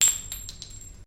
sfx_cassing_drop_2.mp3